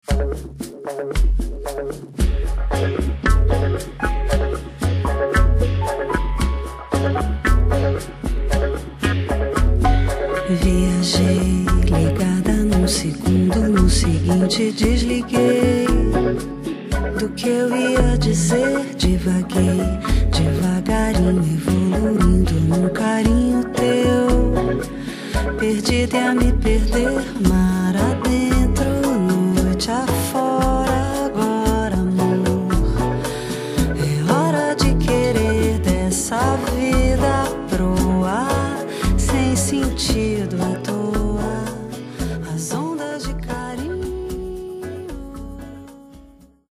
Sensuous samba, bossa nova and jazz from Rio de Janeiro, S